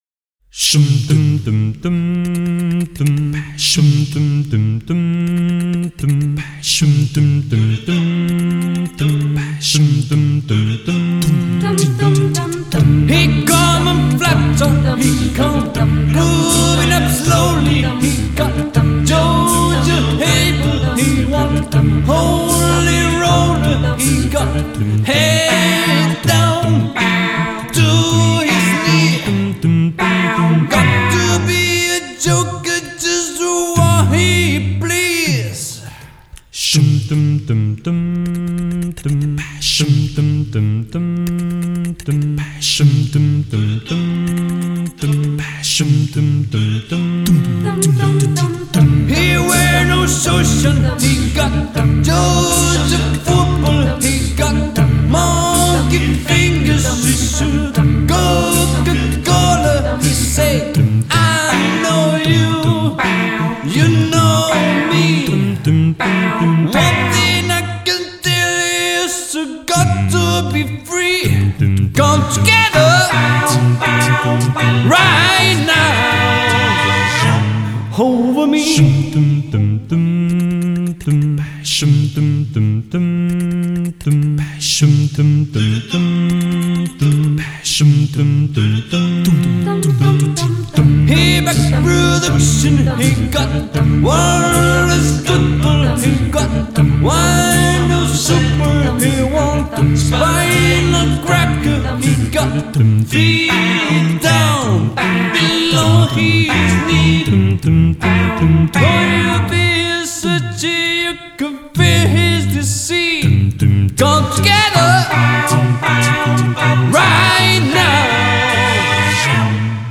a vocal band